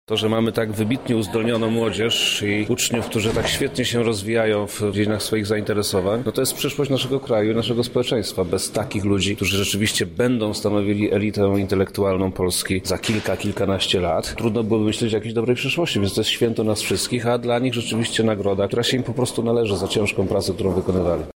Tylko najbardziej pracowici zasługują na nagrodę – mówi Wojewoda Lubelski Przemysław Czarnek: